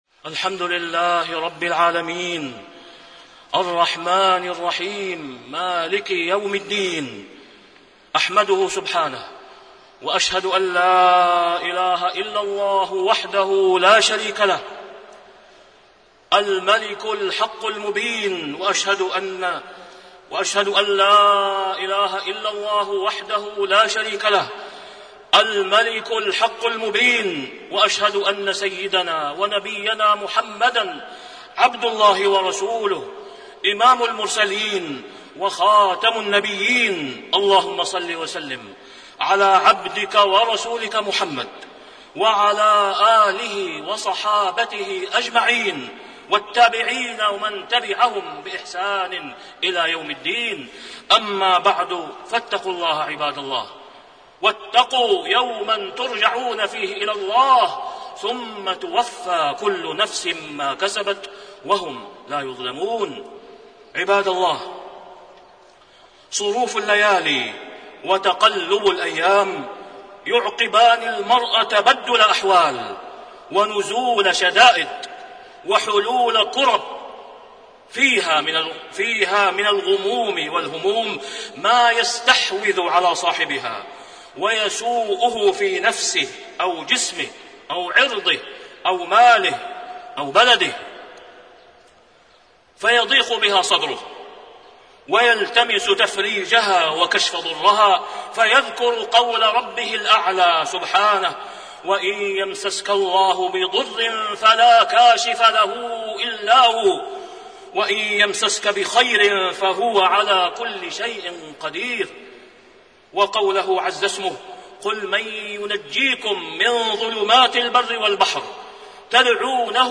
تاريخ النشر ١١ جمادى الأولى ١٤٣٢ هـ المكان: المسجد الحرام الشيخ: فضيلة الشيخ د. أسامة بن عبدالله خياط فضيلة الشيخ د. أسامة بن عبدالله خياط الأخوة الإسلامية ولوازمها